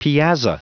Prononciation du mot piazza en anglais (fichier audio)
Prononciation du mot : piazza
piazza.wav